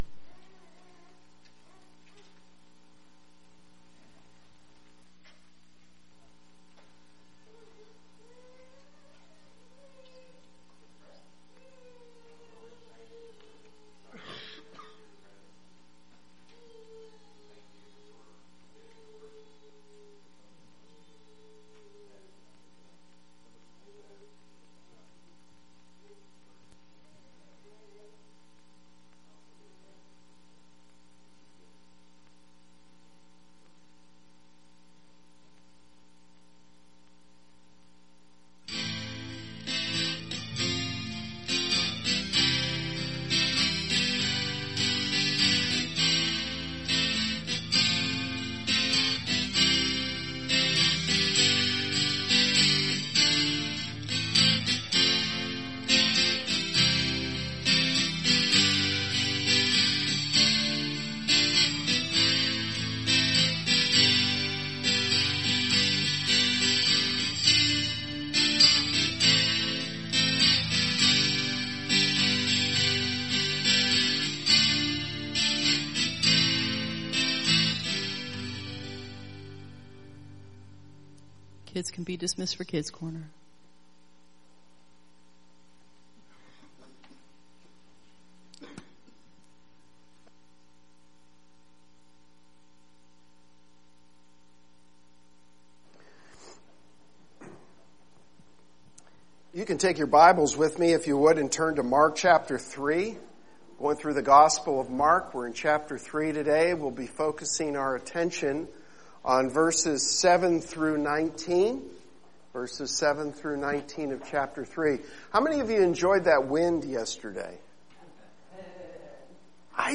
Sermon 10 April Mark 3:7-19